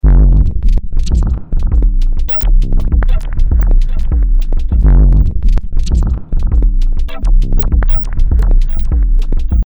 Diese habe ich als Audiodatei im wav-Format mit 24 Bit exportiert, in Geist importiert, Slices angelegt, auf die Pads verteilt und diese dann zunächst mit dem Timestretching (Sie erinnern sich? Der Zynaptiq-Algorithmus …) bearbeitet und dem Ganzen noch ein paar hübsche Effekte kredenzt.
Herausgekommen ist eine Art Experimental-Glitch-Style: